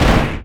IMPACT_Generic_13_mono.wav